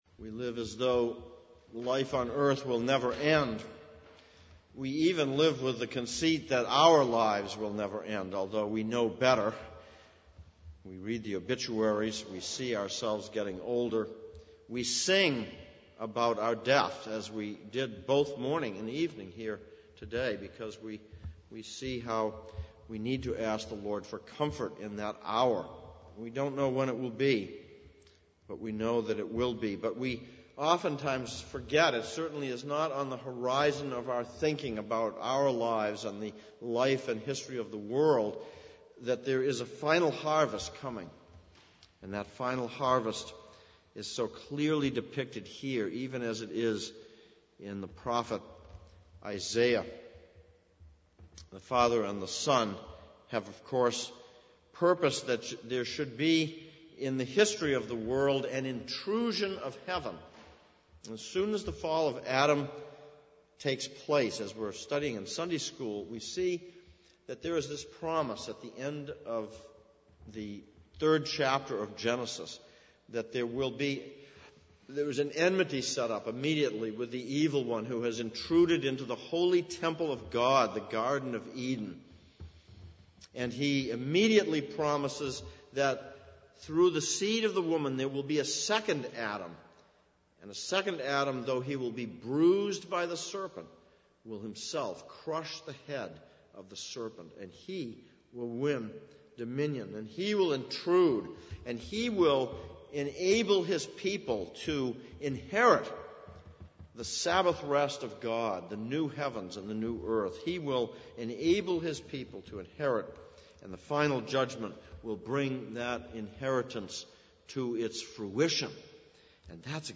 Exposition of Revelation Passage: Revelation 14:14-20, Isaiah 63:1-19 Service Type: Sunday Evening « 21.